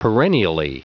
Prononciation du mot perennially en anglais (fichier audio)
Prononciation du mot : perennially